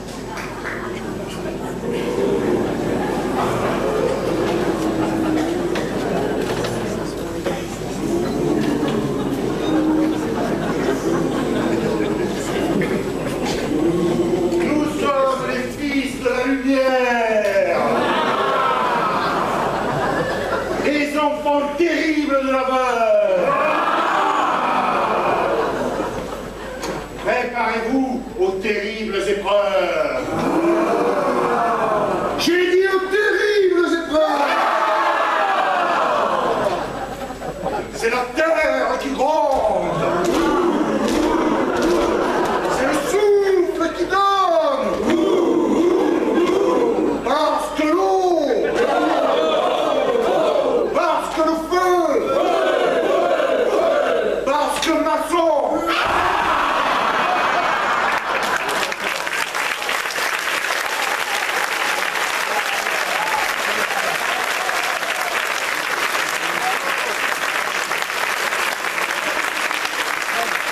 Enregistrement Festival 2017